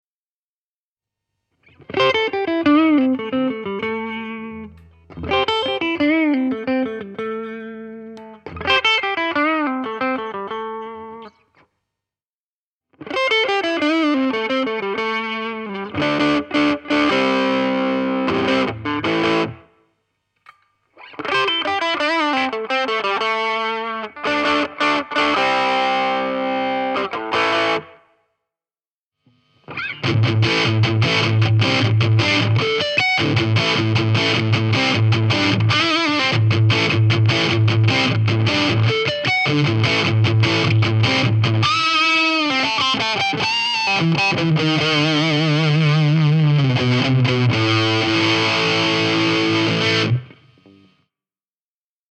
ashtone_ae_602_wh_demo.mp3